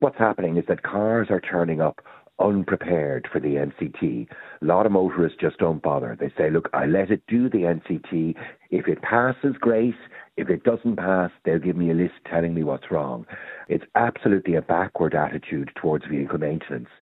Transport commentator